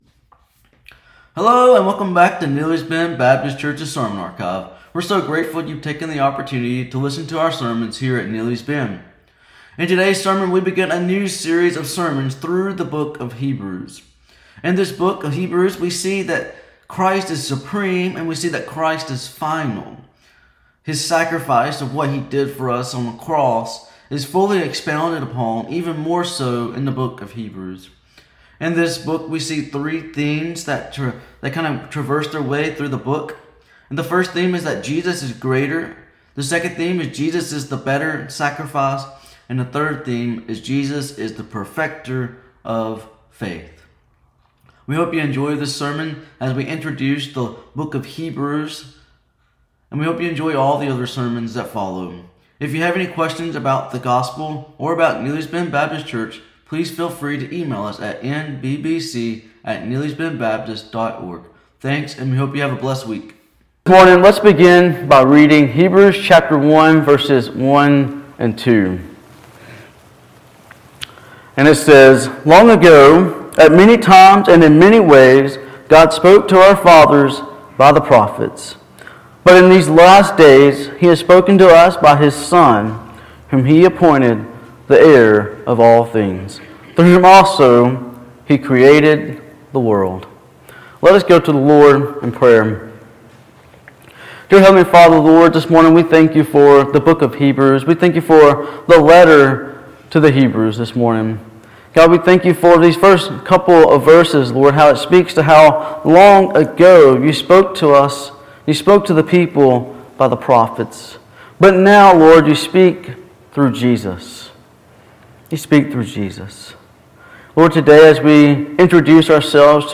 Sermons | Neely's Bend Baptist Church